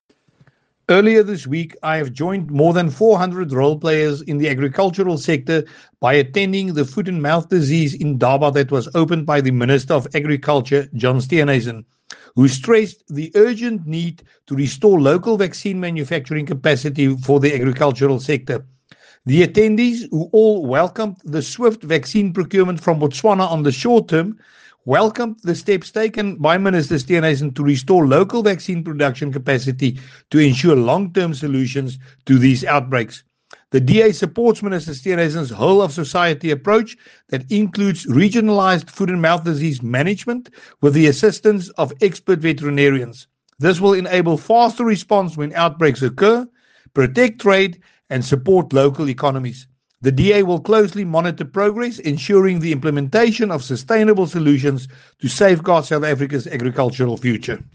Afrikaans soundbites by Willie Aucamp MP.
English-Willie-Aucamp-2.mp3